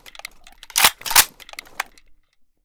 bizon_unjam.ogg